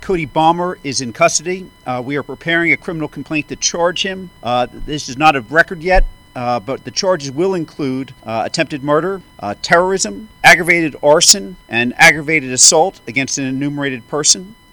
Pennsylvania District Attorney Fran Chardo gave details on the Harrisburg suspect’s charges…